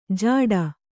♪ jāḍa